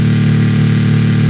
声音的制造者" 微波
描述：微波炉运行时产生的嘈杂声，更确切地说，是这种电器的发动机产生的声音。
标签： 微波 声音 发动机
声道立体声